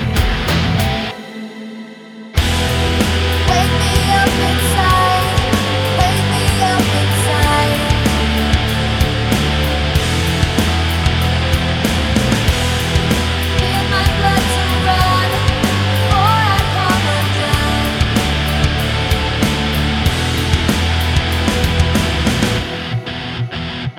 Duet Version Rock 3:48 Buy £1.50